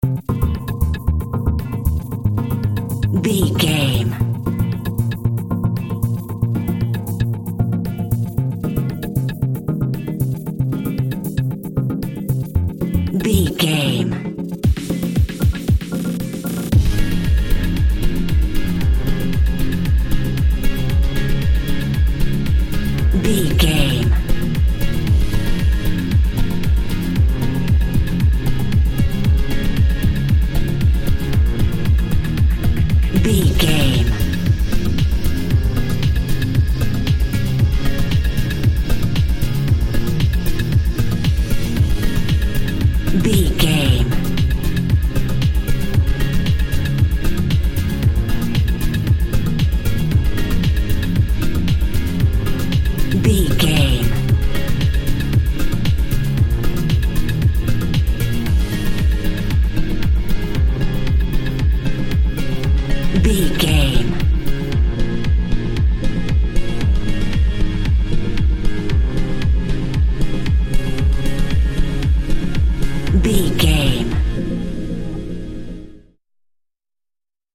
Aeolian/Minor
ethereal
dreamy
cheerful/happy
groovy
synthesiser
drum machine
house
electro dance
techno
trance
synth bass
upbeat